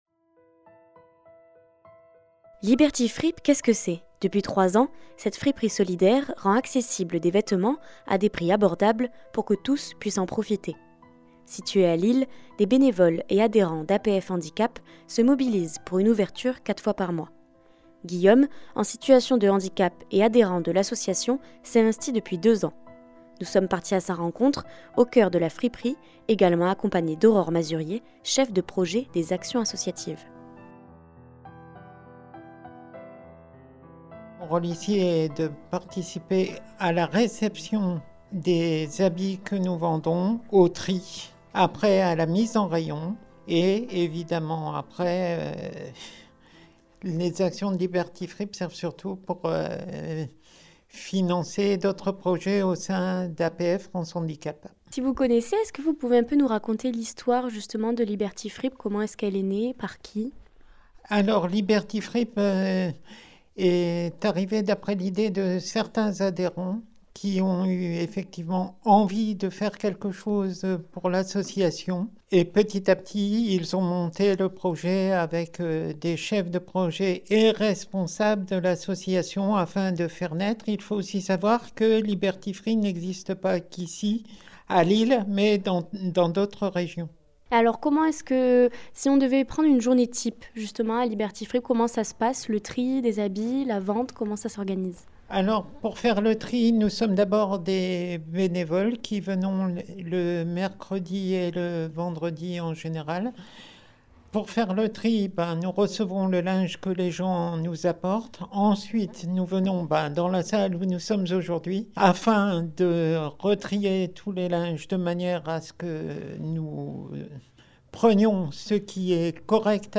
3. REPORTAGES